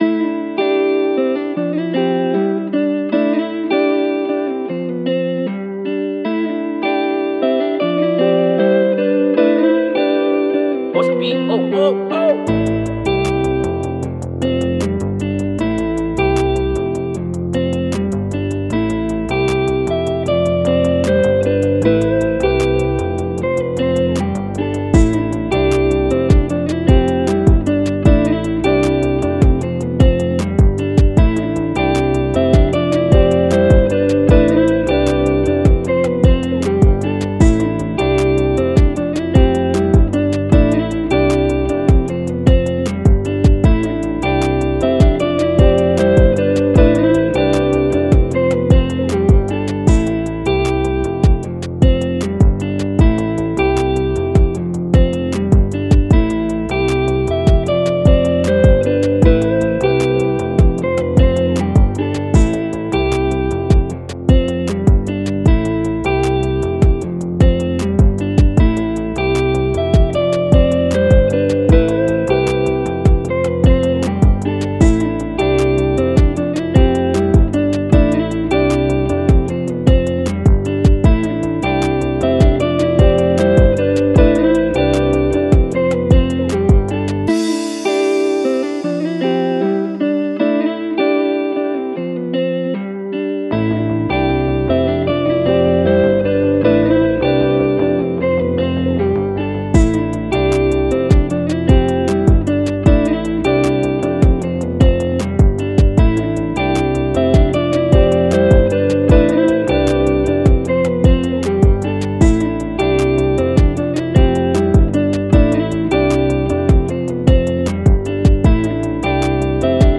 Time – (2:29)　bpm.154